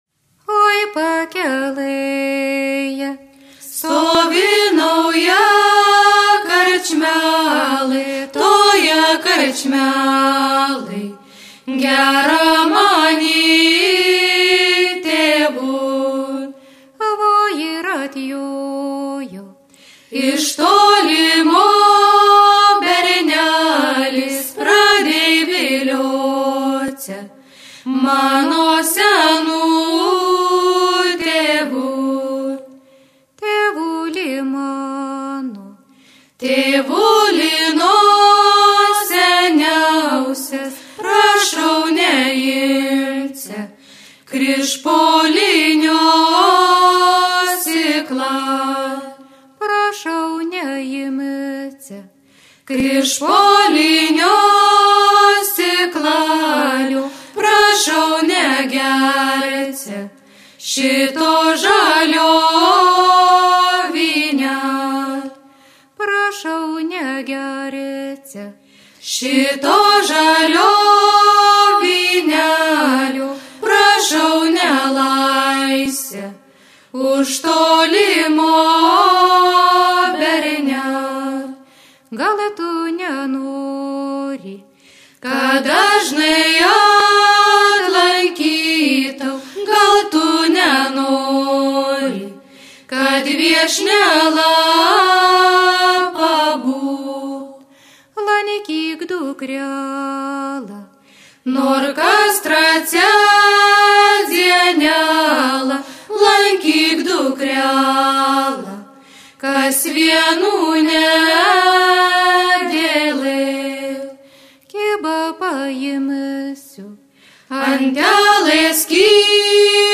vestuvinė, piršlybų
merginų grupė